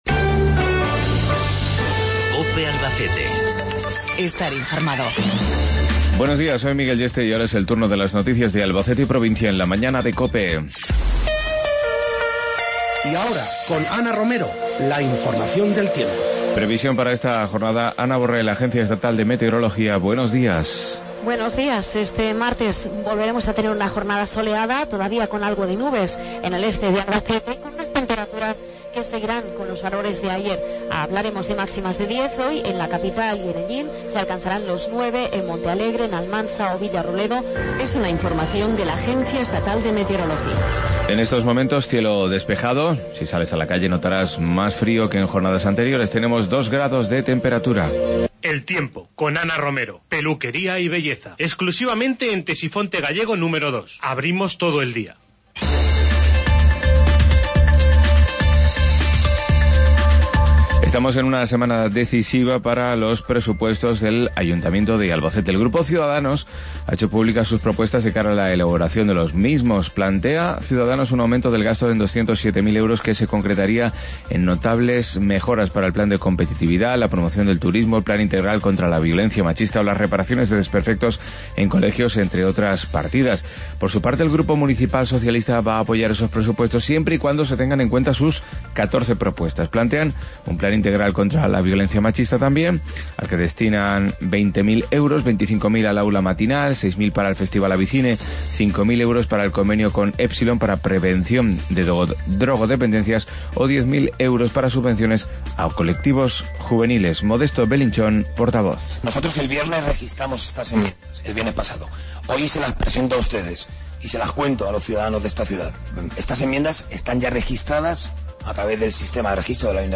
161220 Informativo 0755